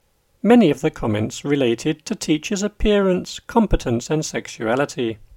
DICTATION 7